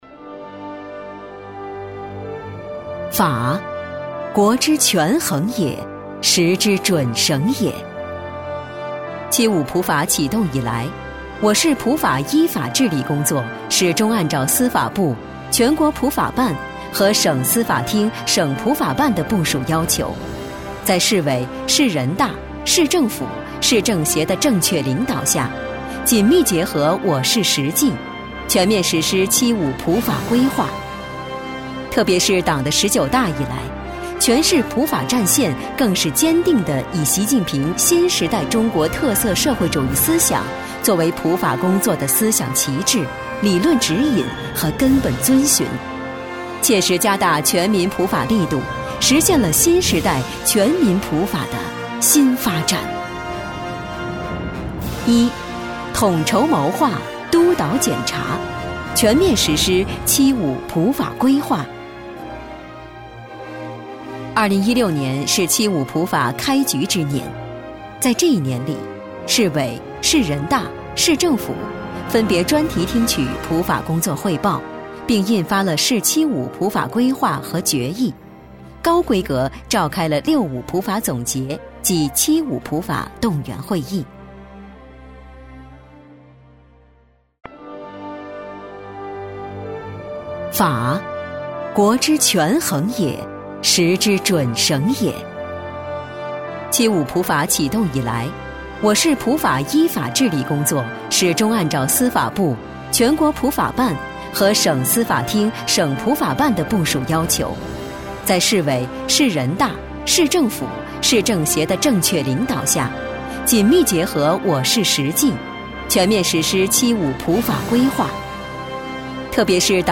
国语儿童亲切甜美 、素人 、女绘本故事 、动漫动画游戏影视 、60元/分钟女S129 国语 女声 儿童绘本-多角色【轻快旁白+男孩+奶奶+阿姨+女孩】儿童安全科普 亲切甜美|素人